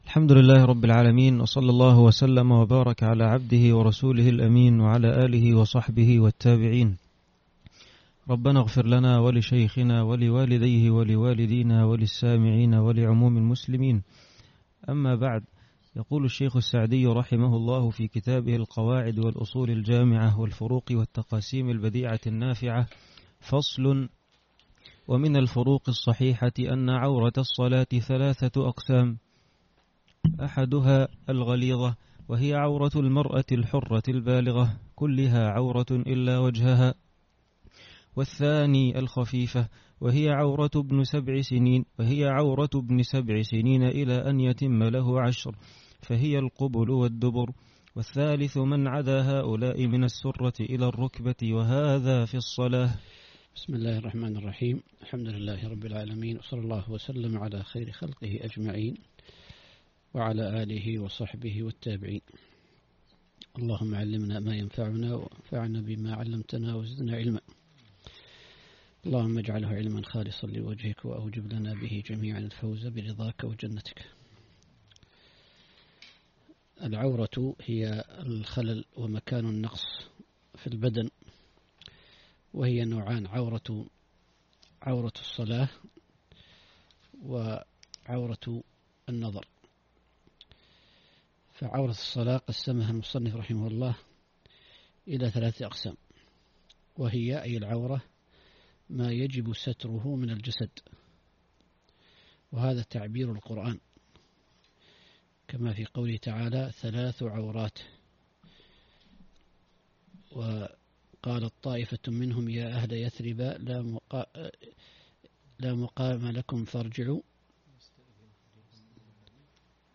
4- القسم الثاني (الفروق والتقاسيم البديعة) - الدرس الرابع